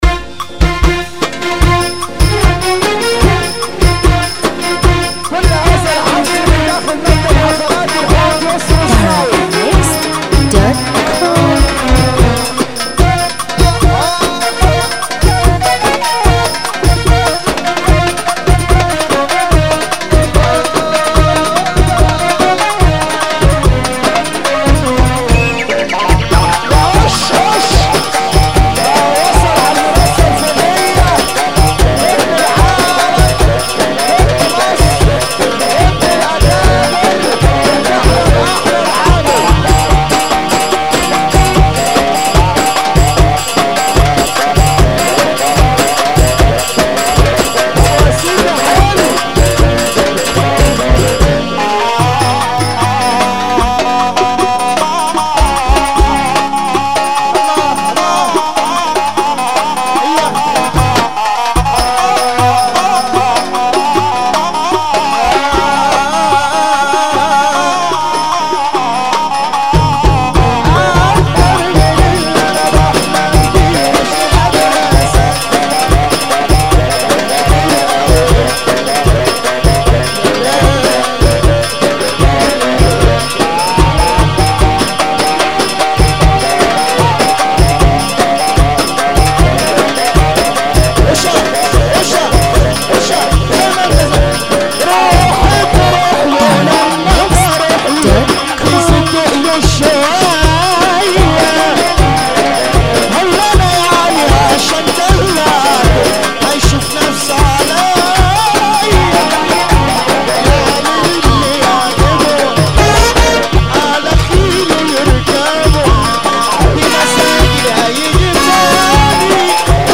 موال
شعبى جديد